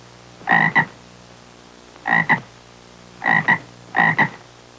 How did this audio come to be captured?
Download a high-quality bullfrog sound effect.